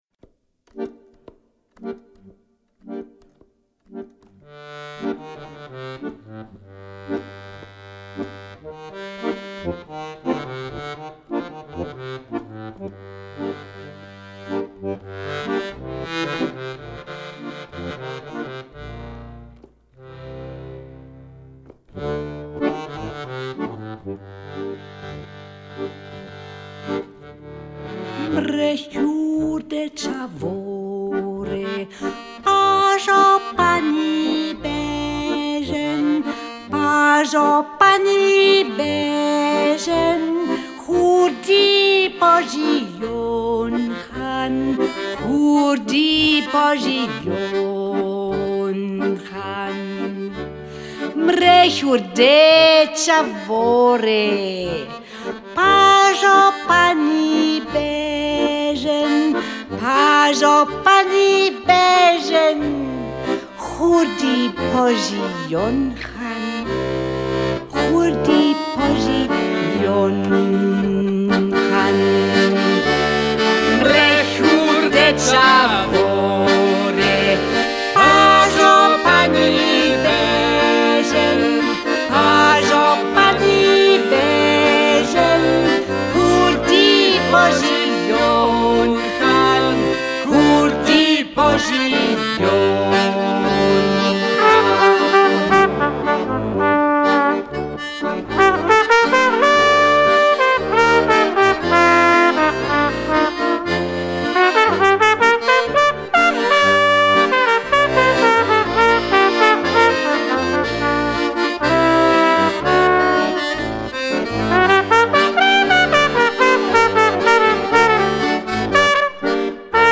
altem, mündlich überliefertem Volksliedgut aus Osteuropa